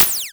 close_004.ogg